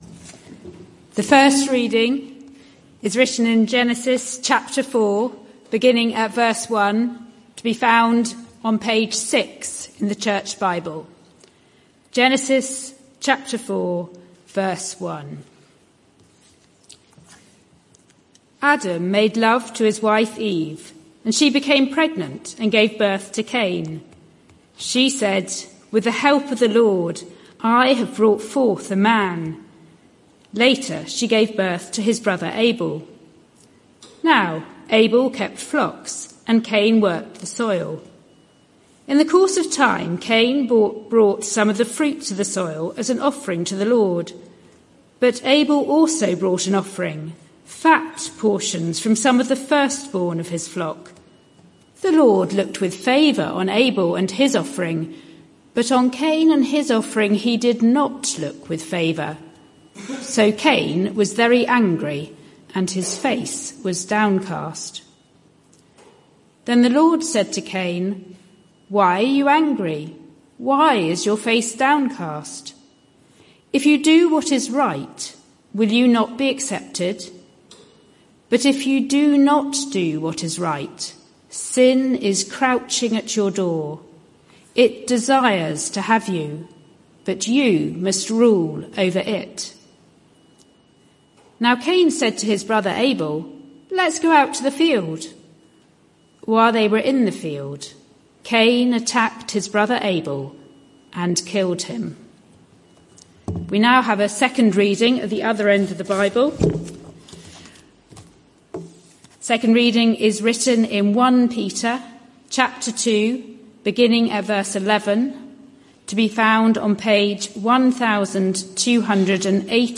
This sermon is part of a series: